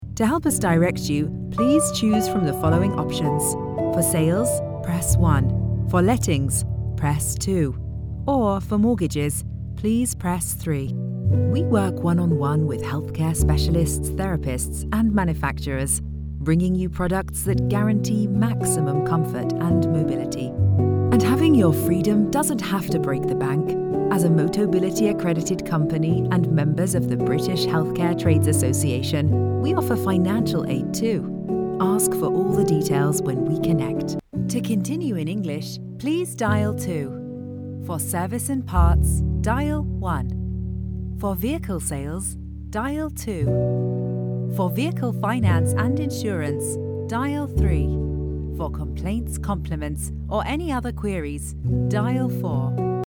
IVR
I have a professional home recording studio and have lent my voice to a wide range of high-profile projects.
LA Booth, Rode, Audient id4
DeepLow
TrustworthyAuthoritativeConfidentFriendlyExperiencedReliable